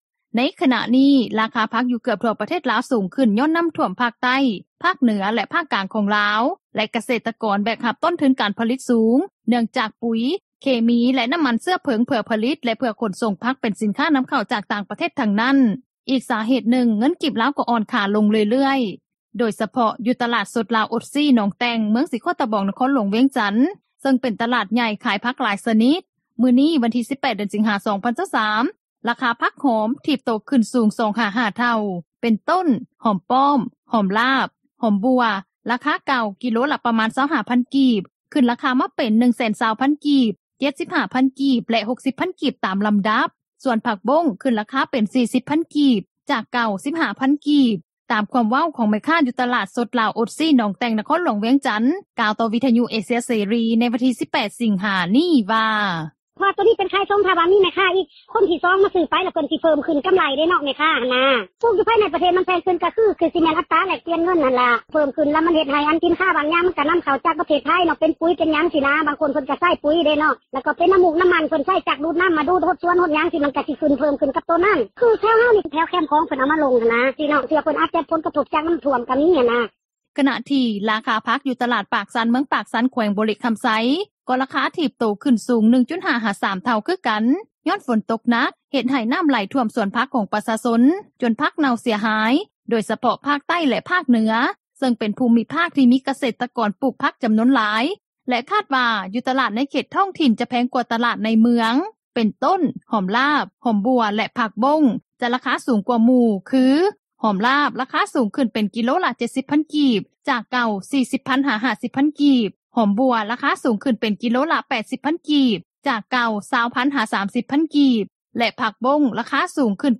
ດັ່ງແມ່ຄ້າຂາຍຊີ້ນໝູ ຢູ່ຕລາດປາກຊັນ ເມືອງປາກຊັນ ແຂວງບໍຣິຄໍາໄຊ ກ່າວໃນມື້ດຽວກັນນີ້ວ່າ:
ດັ່ງປະຊາຊົນທີ່ຊື້ຜັກ ແລະຊີ້ນມາຄົວກິນ ຢູ່ຕລາດທ້ອງຖິ່ນ ເມືອງນານ ແຂວງຫຼວງພຣະບາງ ກ່າວໃນມື້ດຽວກັນນີ້ວ່າ: